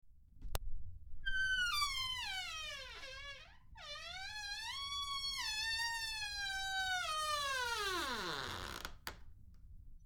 Door Creaking
Door_creaking.mp3